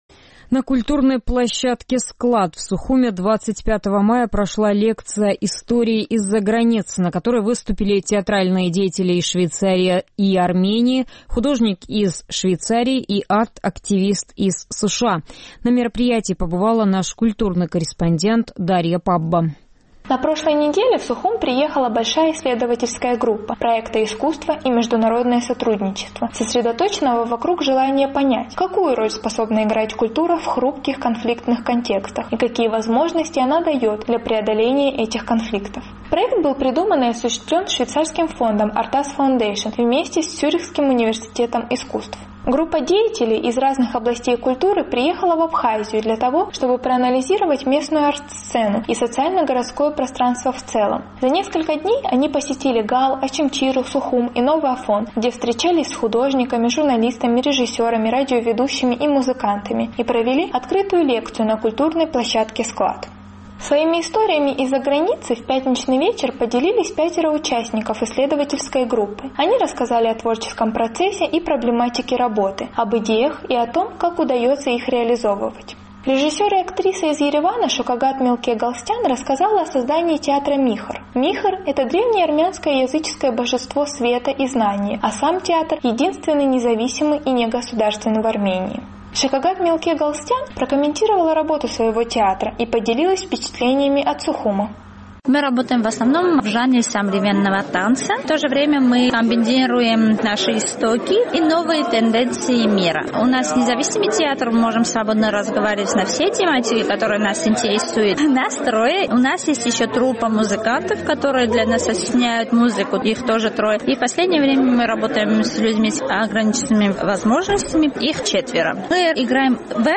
На культурной площадке СКЛАД 25 мая прошла лекция «Истории из-за границы», на которой выступили театральные деятели из Швейцарии и Армении, кинорежиссер из Мианмара, художник из Швейцарии и арт-активист из США.